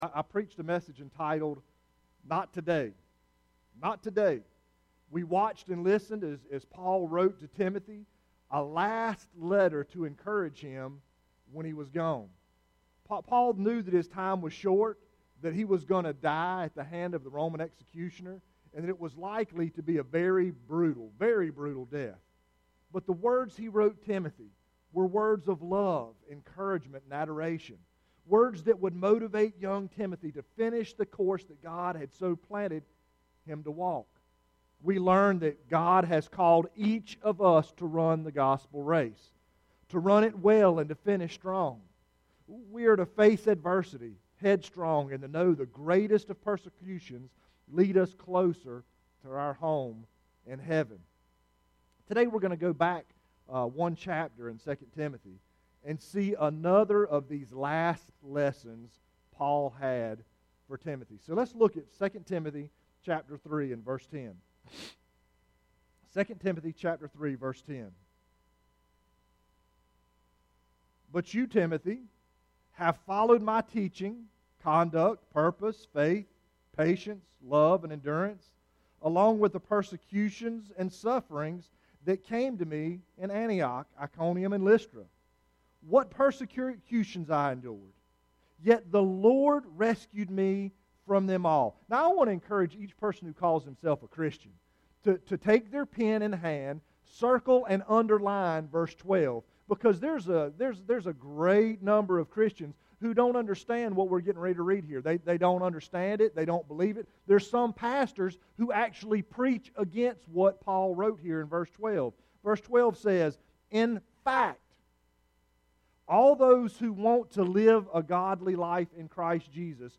Chevis Oaks Baptist Church Sermons